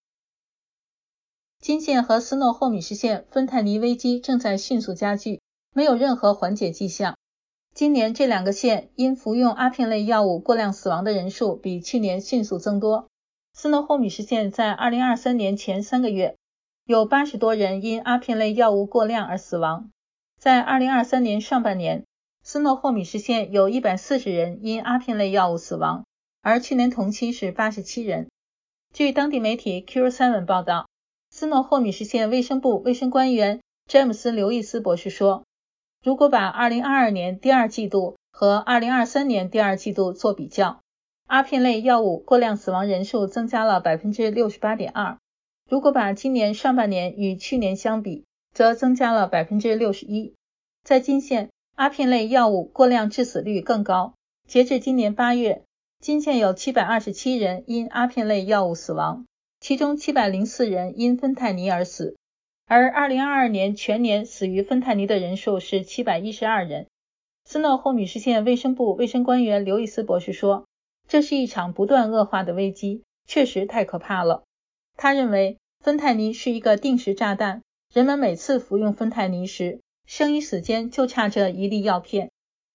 新聞廣播